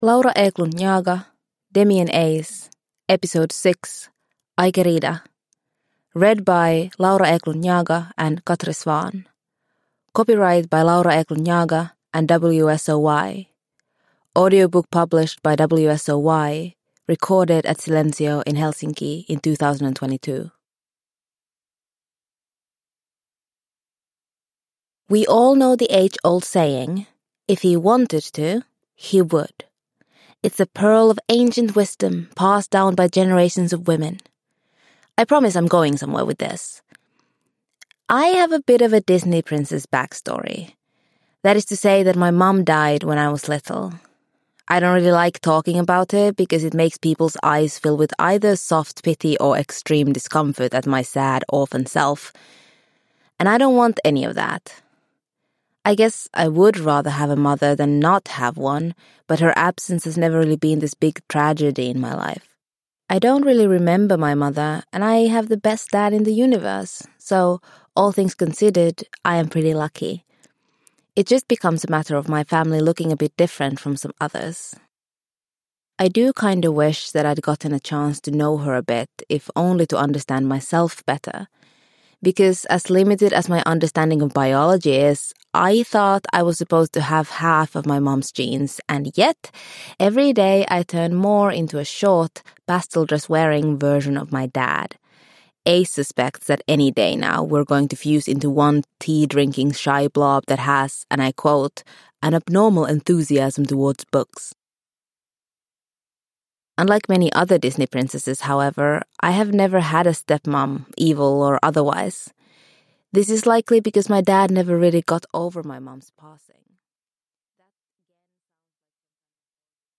Demi and Ace 6: Ai Querida – Ljudbok – Laddas ner